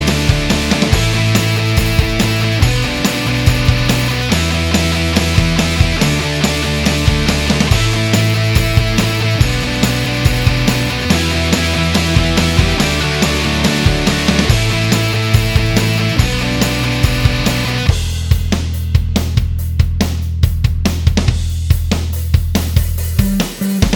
FX Guitars Only Rock 3:42 Buy £1.50